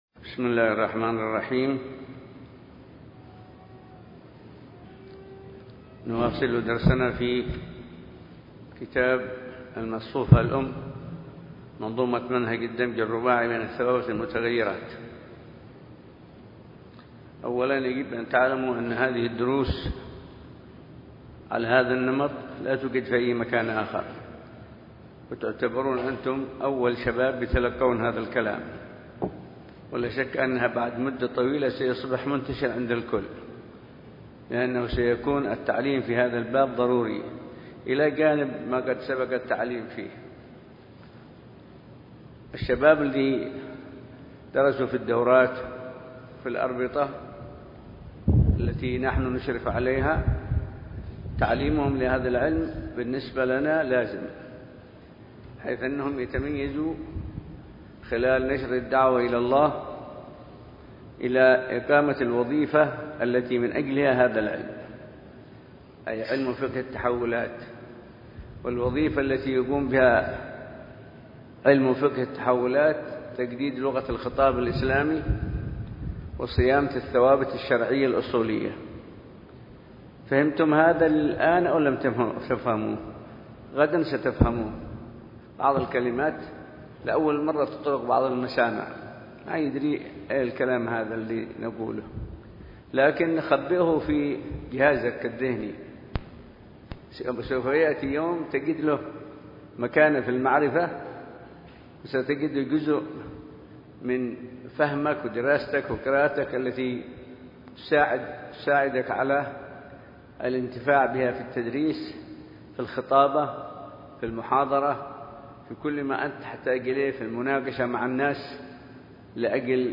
مساء السبت ٢٢ ذو القعدة ١٤٣٩ه‍ بمسجد الأحمدين – شعب الإمام المهاجر – الحسيسة.